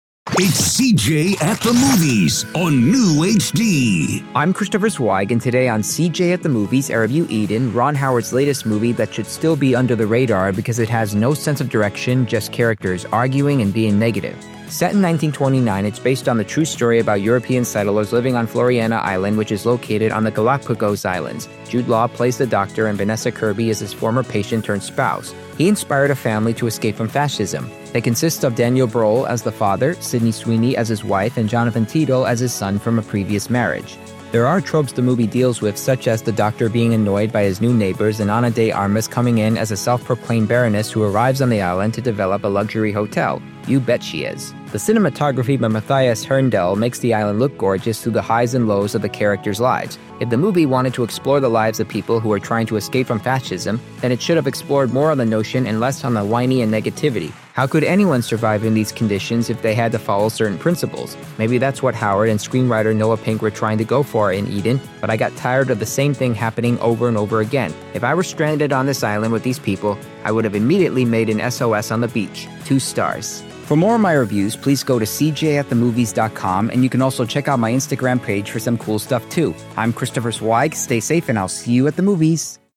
reviews five dangerous movies on the air.